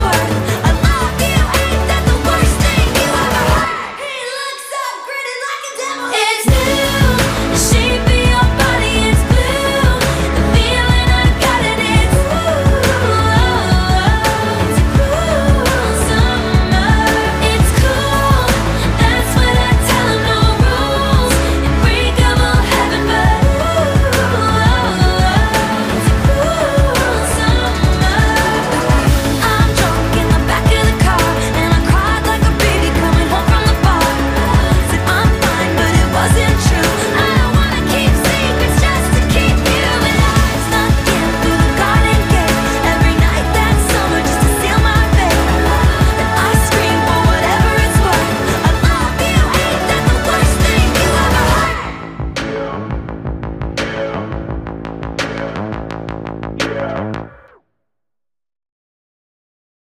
женский голос
атмосферные